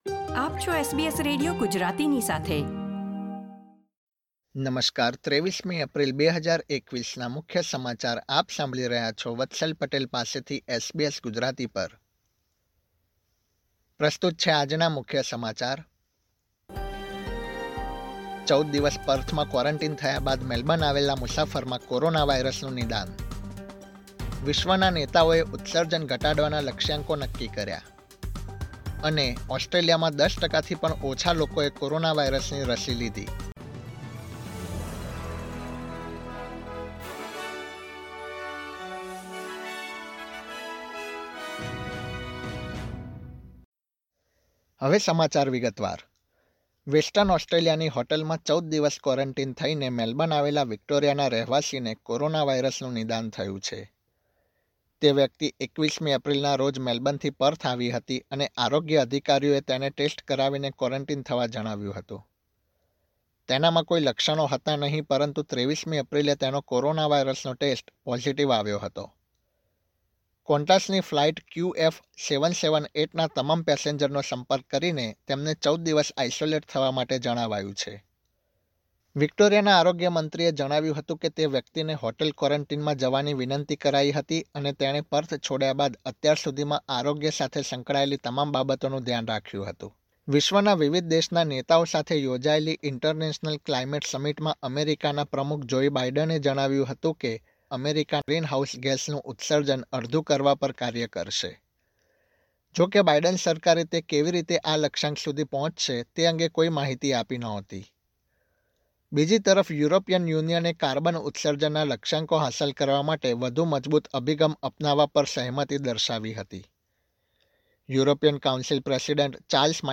SBS Gujarati News Bulletin 23 April 2021
gujarati_2304_newsbulletin.mp3